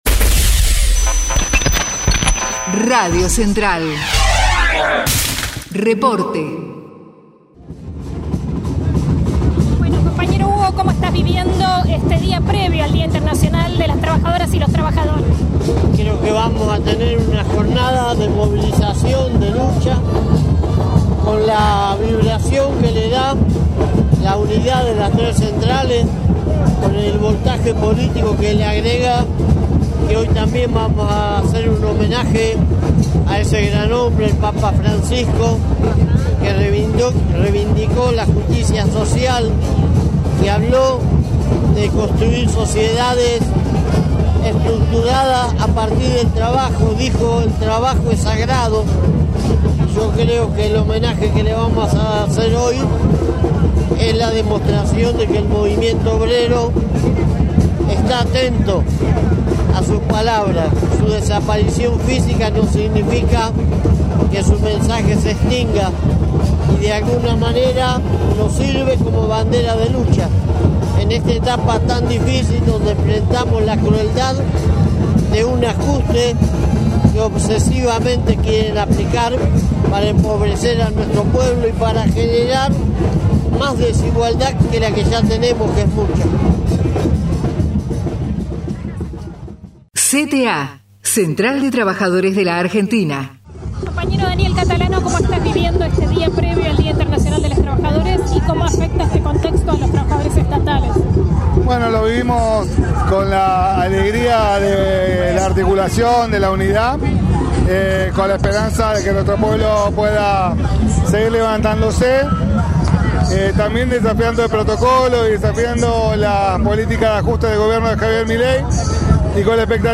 1º DE MAYO: MULTITUDINARIA MARCHA - Testimonios CTA
2025_1_mayo_marcha.mp3